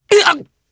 Theo-Vox_Damage_jp_03.wav